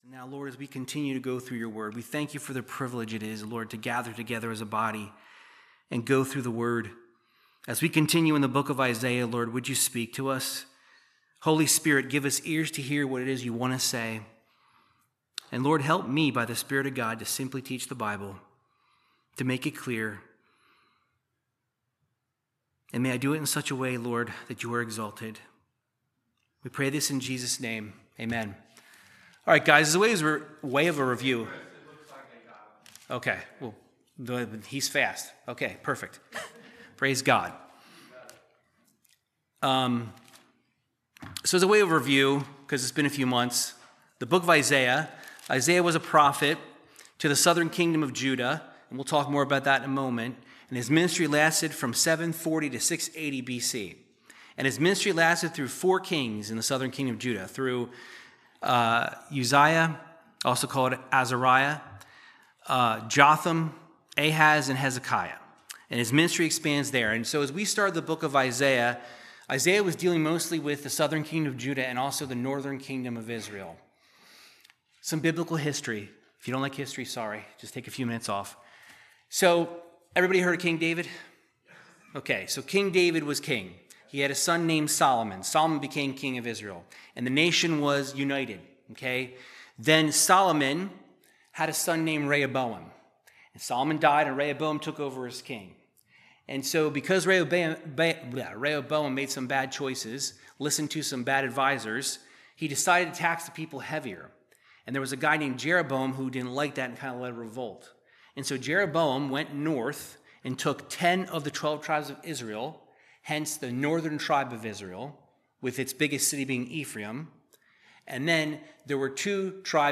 Verse by verse Bible teaching through the book if Isaiah chapter 13